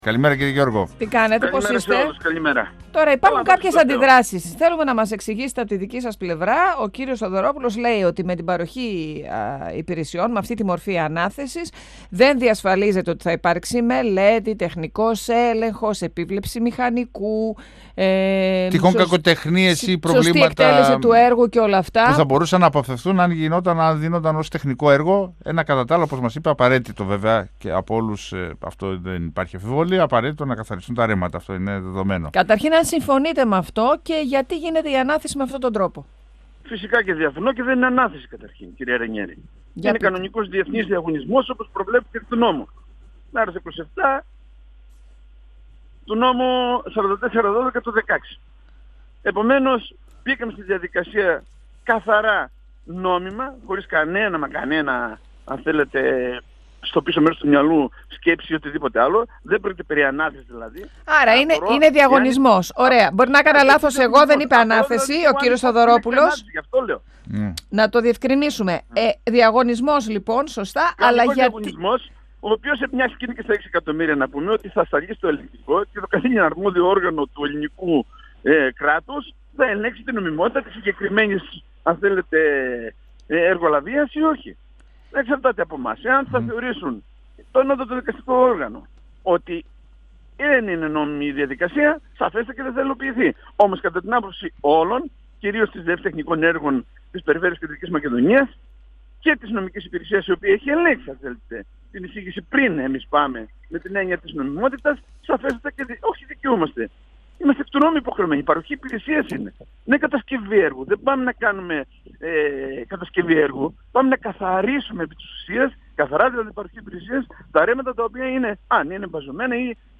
Στην εισαγγελέα του Αρείου Πάγου βρίσκονται οι προσφυγές των περιφερειών με αφορμή τη μεταφορά της αρμοδιότητας καθαρισμού των ρεμάτων στις περιφέρειες. Όπως είπε μιλώντας στον 102φμ ο αντιπερειάρχης Γιάννης Γιώργος, η μεταφορά έγινε δίχως να έχουν εξασφαλιστεί πριν οι πόροι αλλά και το ανθρώπινο δυναμικό που χρειάζεται για τα έργα αυτά.